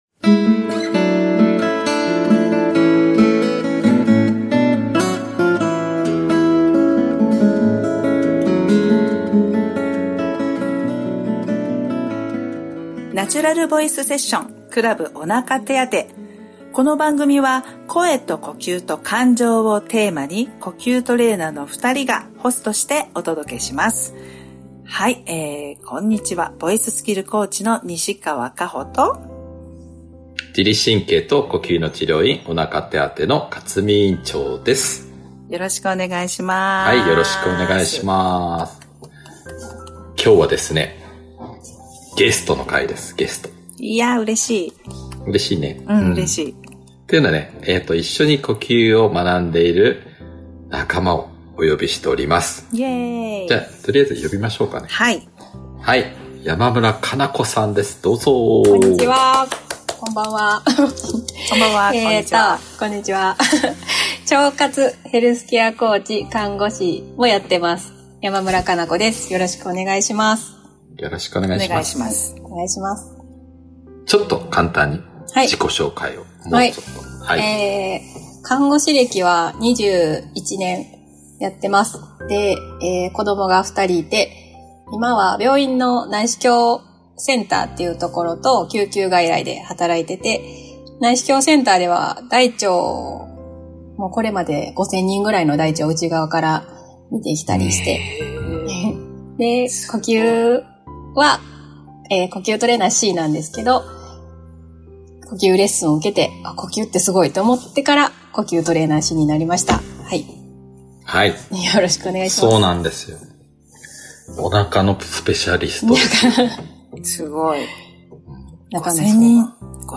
Audio Channels: 1 (mono)